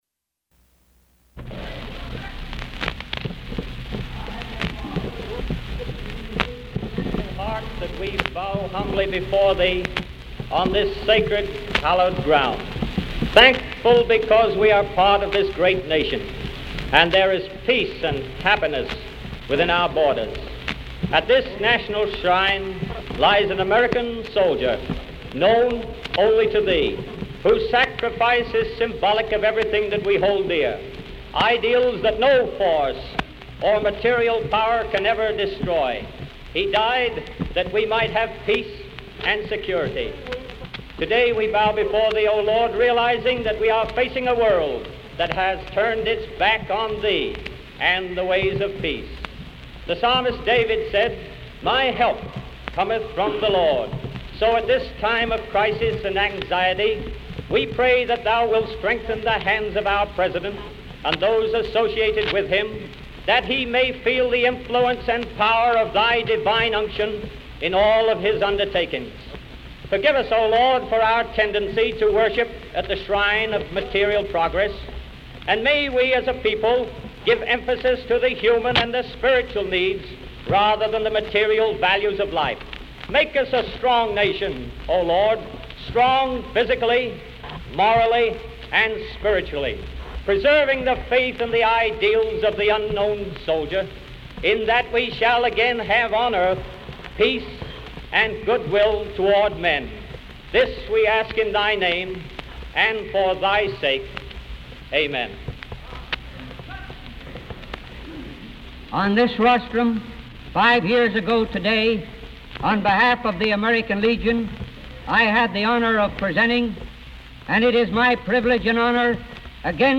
U.S. President Franklin D. Roosevelt speaks at the tomb of the unknown soldier
Franklin D. Roosevelt speaks at the grave of the unknown soldier in Arlington Cemetery on Armistice Day, 1940.